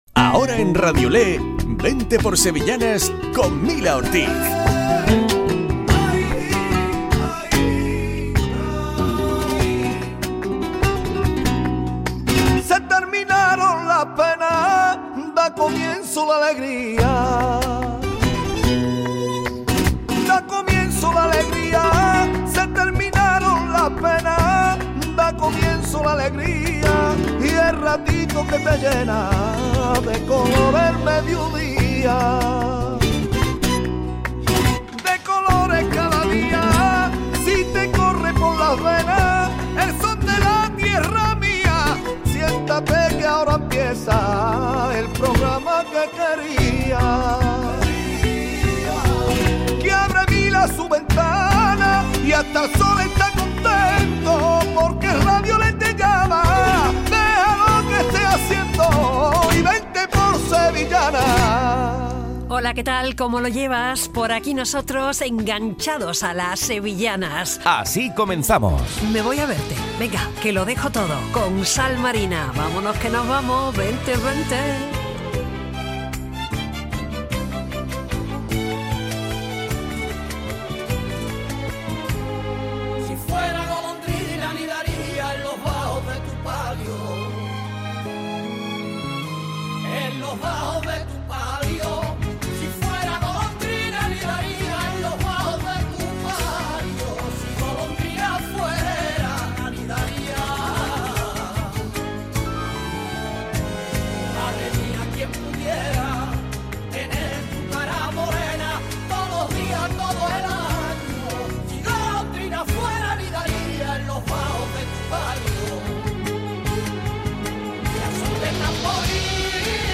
Programa dedicado a las sevillanas. Hoy un guiño al Polígono de San Pablo.